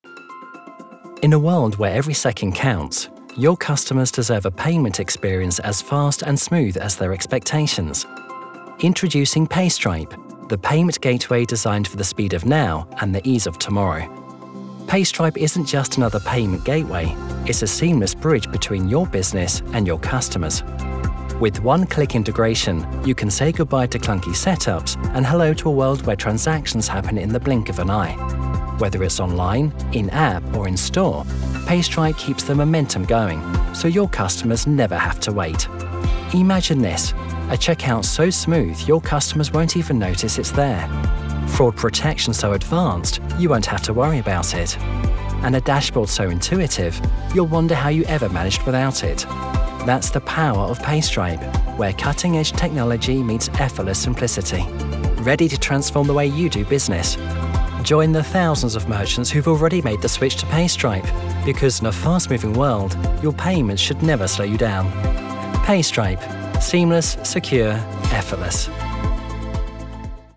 Anglais (Britannique)
Commerciale, Naturelle, Distinctive, Amicale, Jeune, Corporative
Corporate